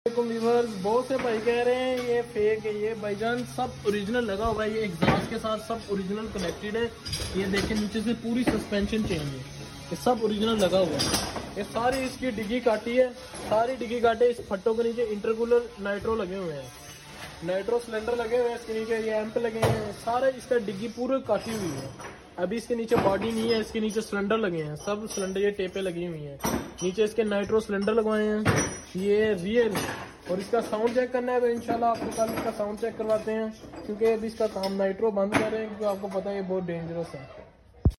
Nitro super boost power sound effects free download